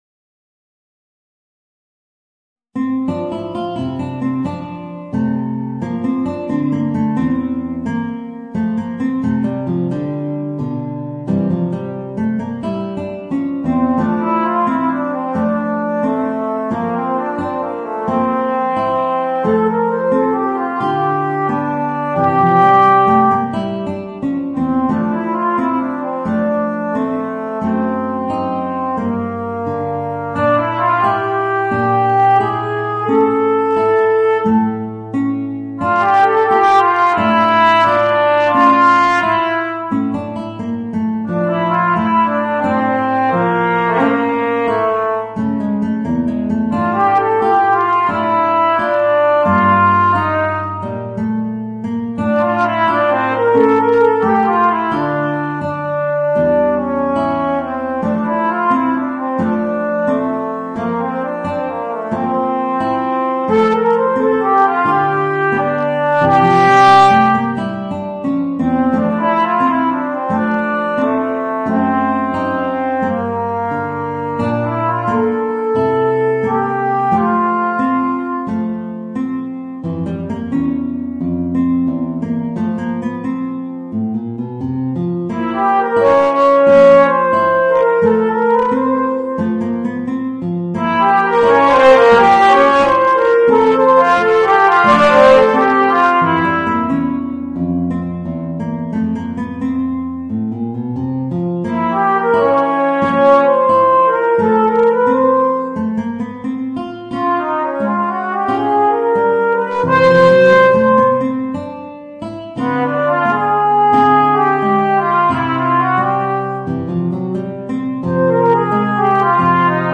Voicing: Guitar and Alto Trombone